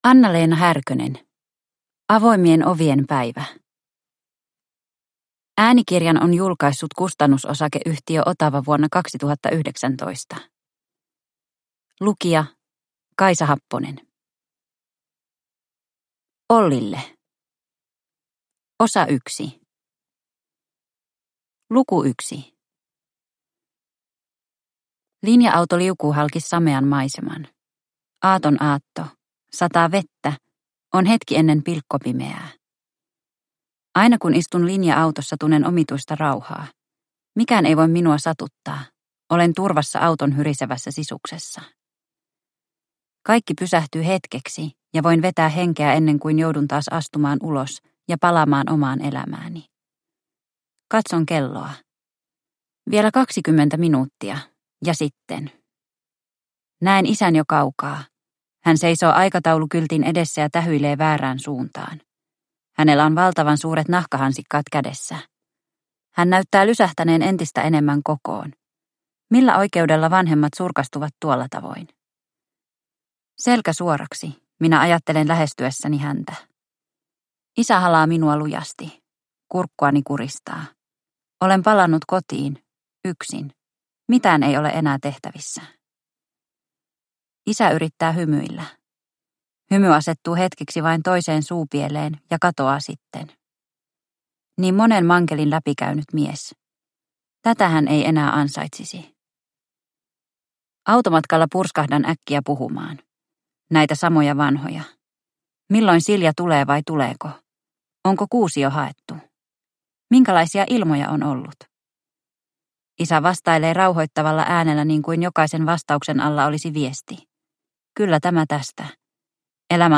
Avoimien ovien päivä – Ljudbok – Laddas ner